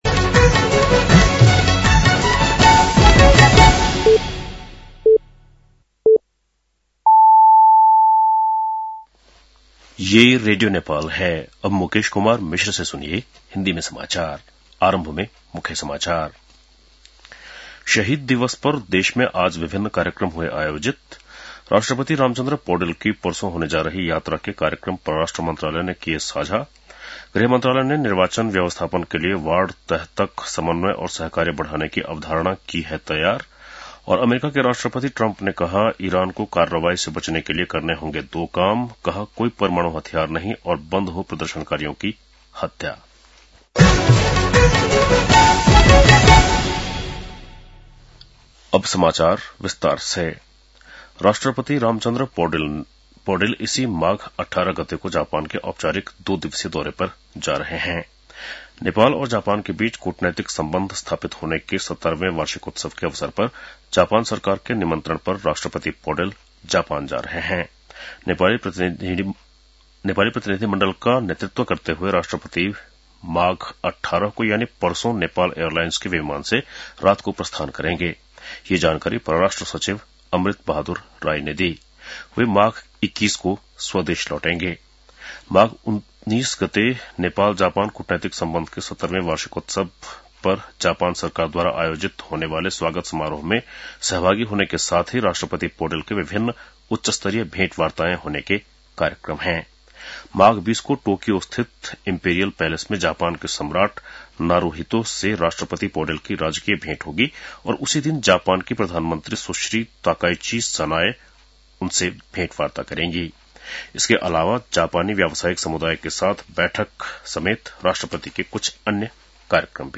बेलुकी १० बजेको हिन्दी समाचार : १६ माघ , २०८२